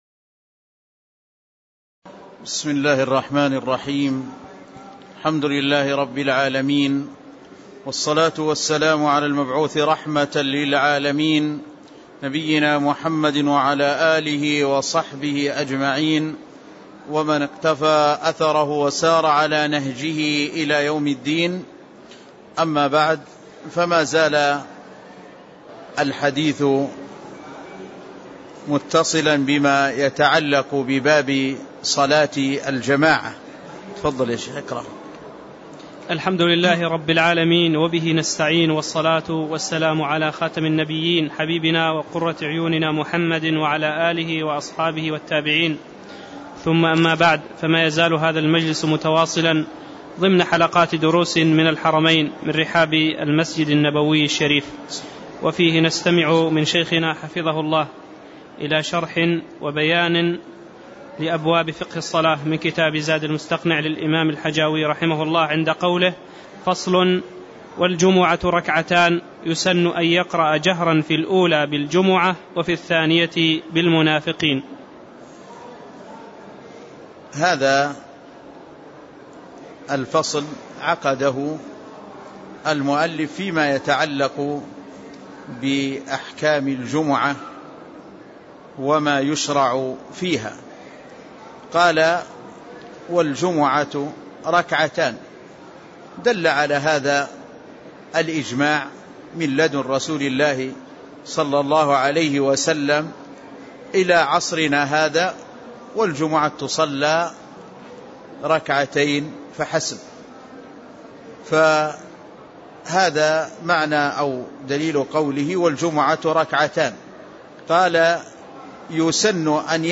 تاريخ النشر ٦ ربيع الأول ١٤٣٦ هـ المكان: المسجد النبوي الشيخ